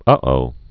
(ŭō)